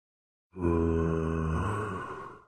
Sound Effects
Zombie Minecraft